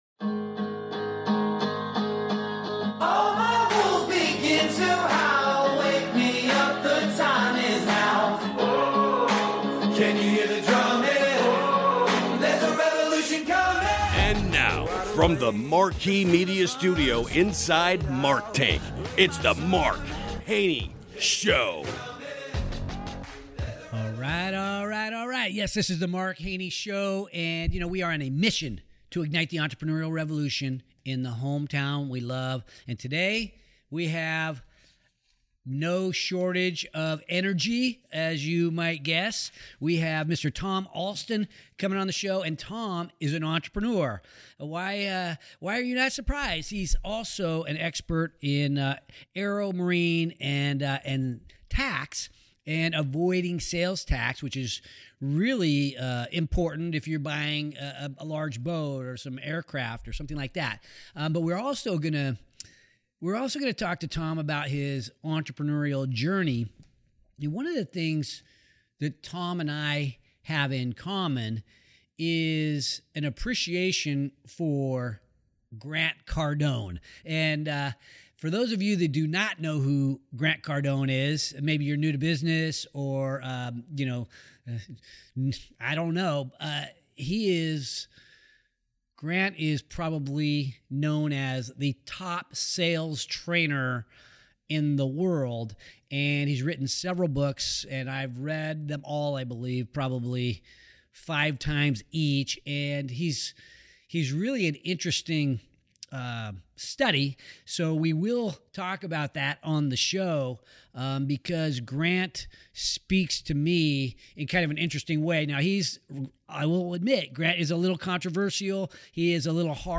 a wide ranging conversation